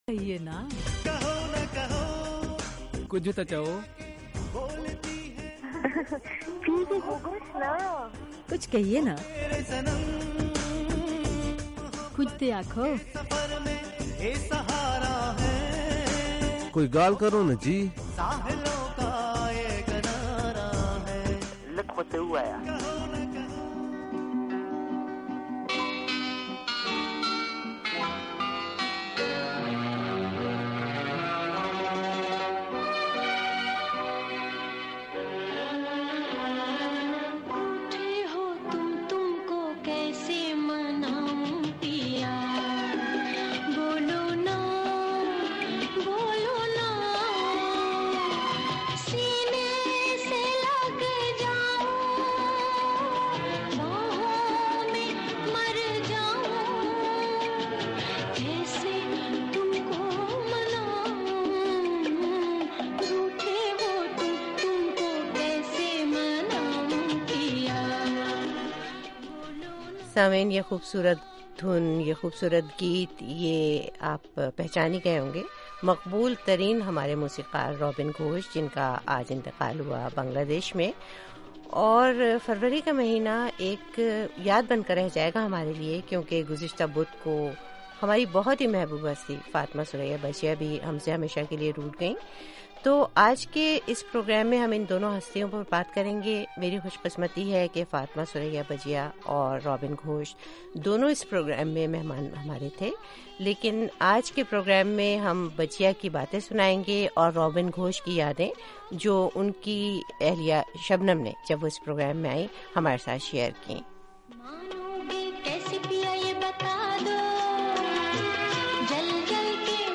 ھمارے اس پروگرام میں دو ایسے مہمان بھی آئے جن کی اب یادیں ہی رہ گئی ہیں ۔۔۔ ۔فروری 2016، ھمارے لئے دو محبوب فنکاروں کی یاد کا مہینہ بن گیا ہے ۔۔۔ اپنے اپنے فن میں یکتا دو ہستیاں ، فاطمہ ثریا بجیا اور روبن گھوش ہمیشہ کےلیے روٹھ گئے ۔ لیکن آج ہم ان کی کچھ باتیں سنیں گے اور سننے والے بھی شریک ہونگے ۔